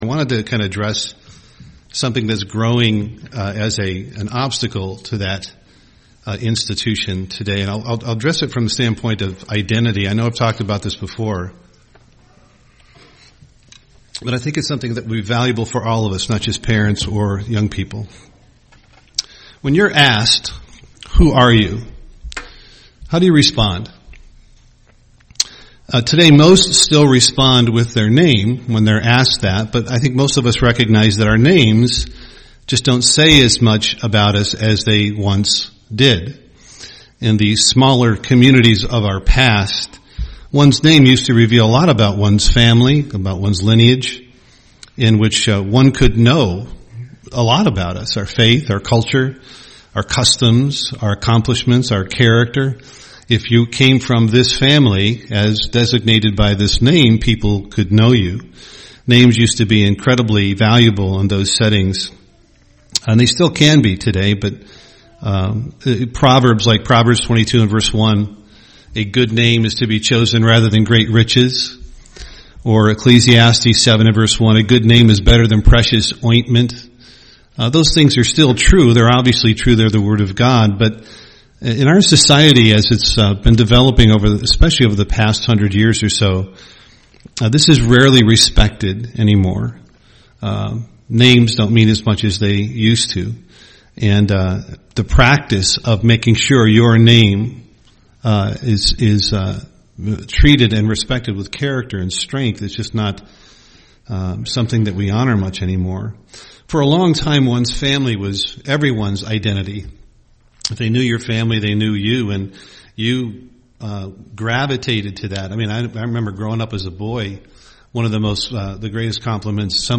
UCG Sermon identity Studying the bible?